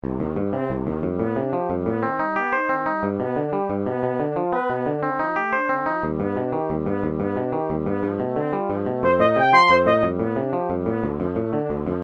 Sonificação do tempo entre balizas de dois orientistas na pr...
Orientista 1 - Trompete / 2 oitavas / track tempo: 6 x Orientista 2 - Guitarra elétrica / 2 oitavas / track tempo: 6 x